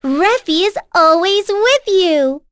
Rephy-Vox_Victory_b.wav